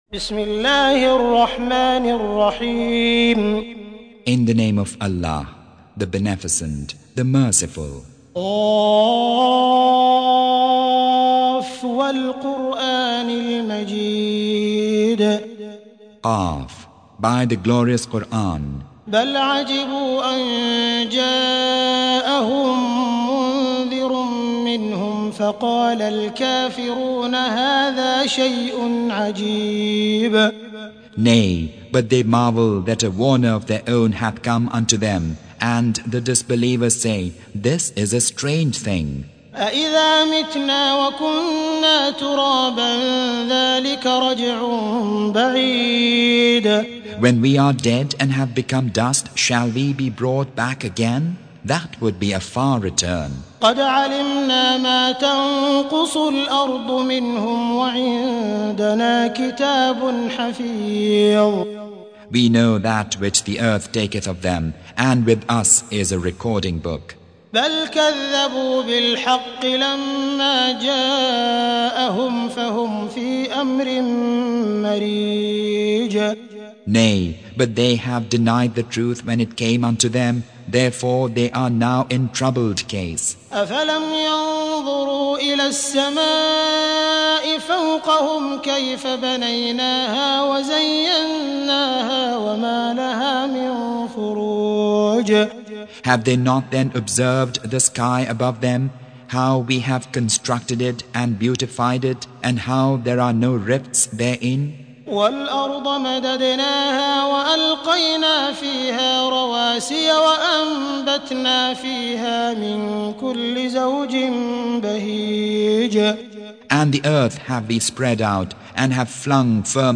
50. Surah Q�f. سورة ق Audio Quran Tarjuman Translation Recitation
Surah Q�f. سورة ق N.B *Surah Includes Al-Basmalah Reciters Sequents تتابع التلاوات Reciters Repeats تكرار التلاوات